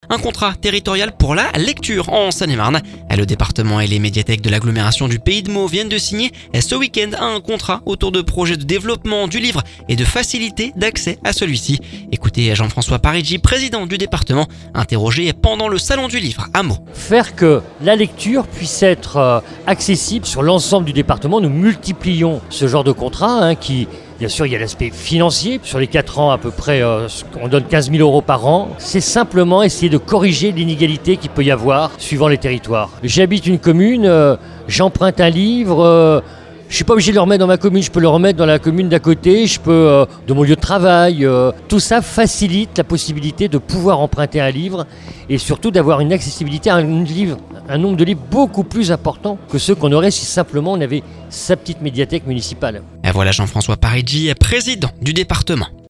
Le Département et les médiathèques de l’agglomération du pays de Meaux viennent de signer ce week-end un contrat autour de projets de développement du livre et de facilité d’accès à celui-ci. Jean-François Parigi, président du département, interrogé pendant le Salon du Livre à Meaux.